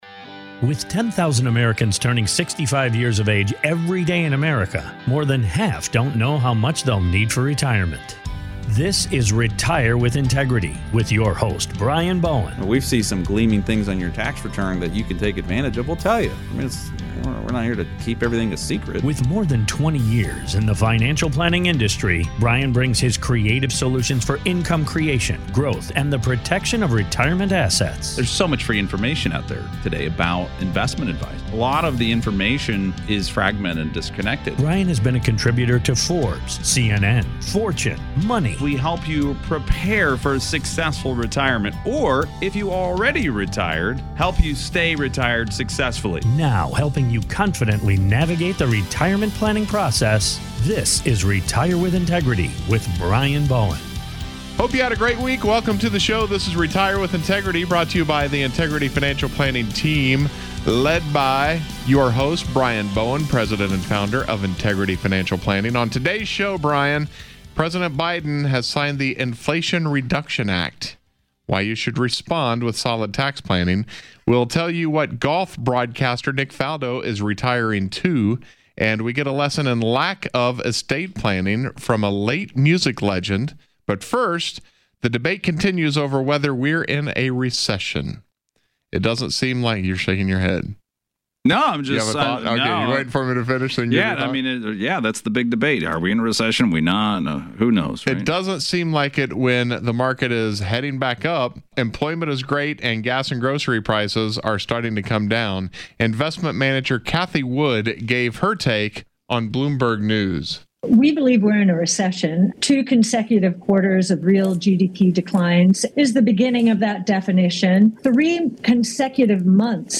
Let the Retire With Integrity radio show help you prepare your finances for tomorrow.